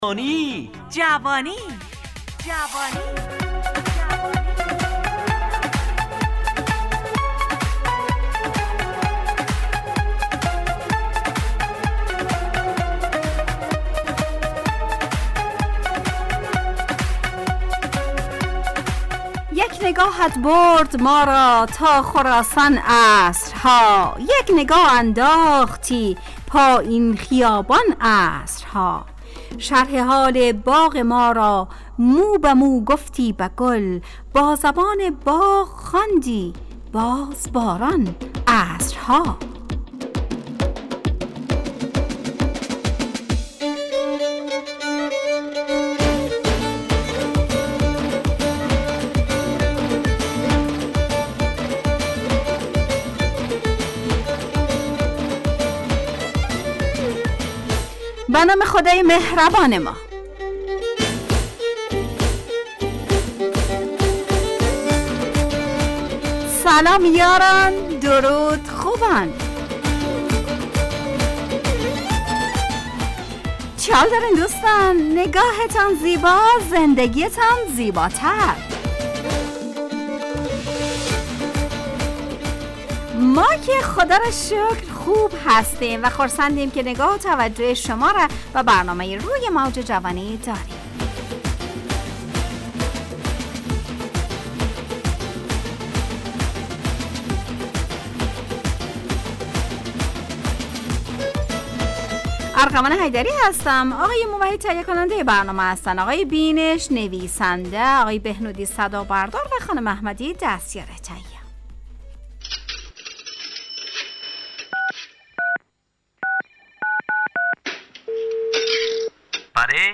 روی موج جوانی، برنامه شادو عصرانه رادیودری.
از شنبه تا پنجشنبه ازساعت 4:45 الی5:55 به وقت افغانستان، طرح موضوعات روز، وآگاهی دهی برای جوانان، و.....بخشهای روزانه جوان پسند. همراه با ترانه و موسیقی مدت برنامه 70 دقیقه .